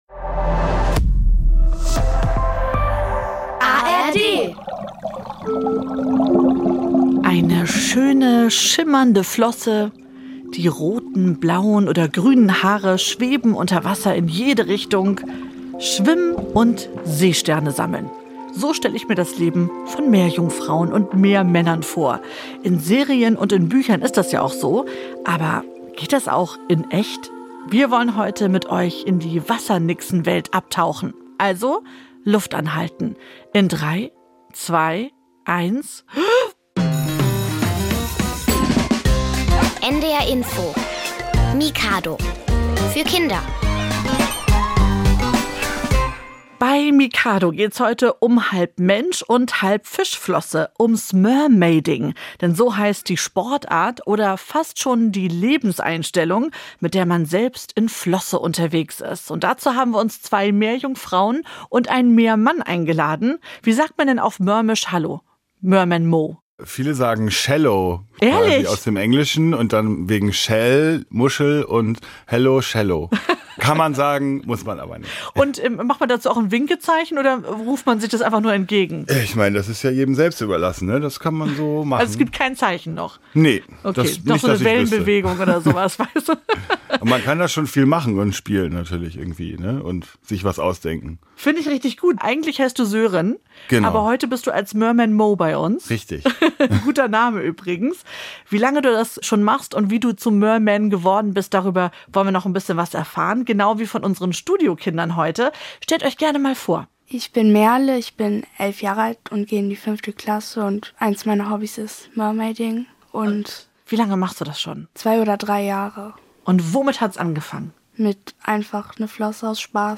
Ein paar von ihnen haben wir als Gäste ins Mikado-Studio eingeladen. Wir sprechen bei Mikado über das neue Hobby Mermaiding und über die magische Unterwasserwelt in den tiefen der Ozeane.